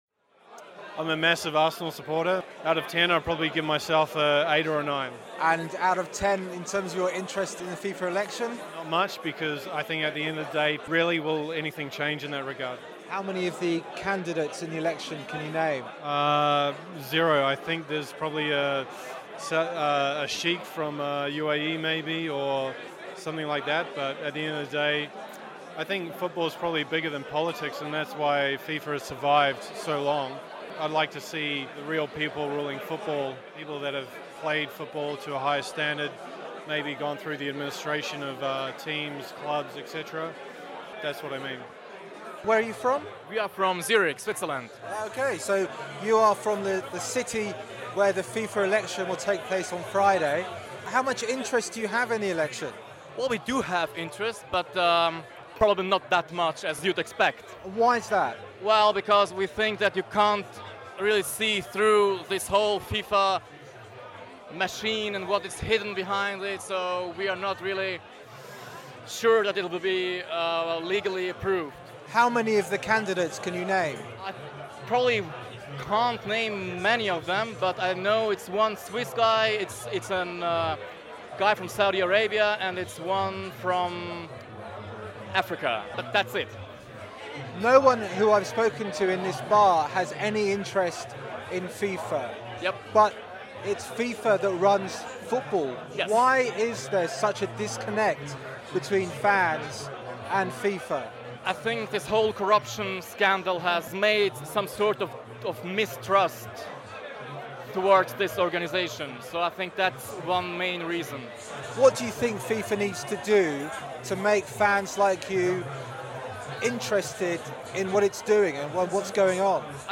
Spoke to some football fans in a Zurich sports bar last night. On this basis, none of the 5 candidates is fit for purpose.